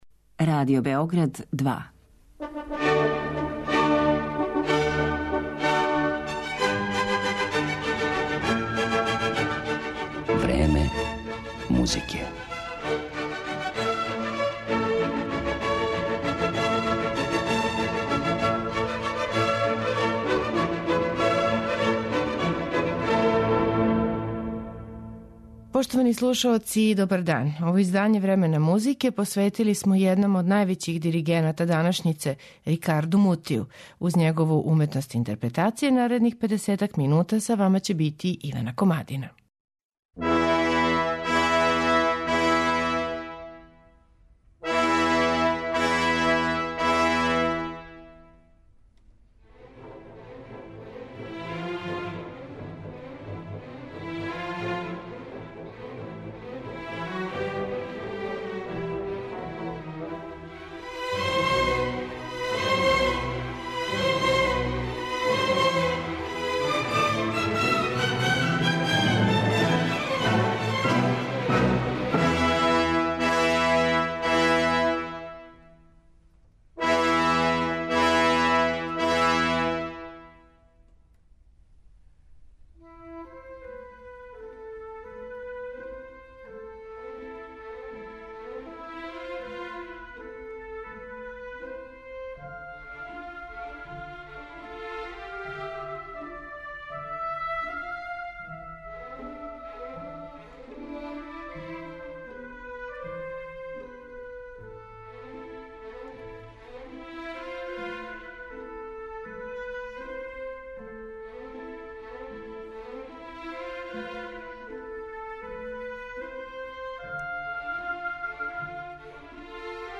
У интерпретацији Рикарда Мутија слушаћете дела Вердија, Хајдна, Леонкавала, Моцарта, Шабријеа и Чајковског.